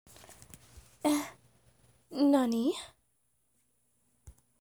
A girl asks do i sound like hatsune miku and says na-nani
Category 🗣 Voices
speak speech voice sound effect free sound royalty free Voices